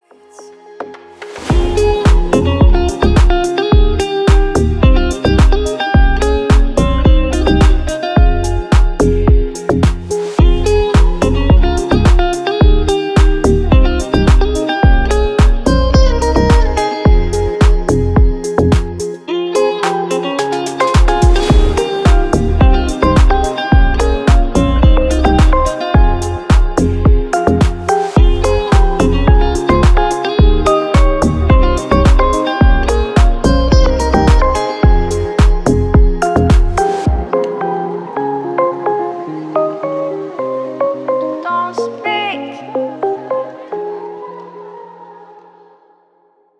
• Качество: 320, Stereo
deep house
спокойные
Cover
расслабляющие
relax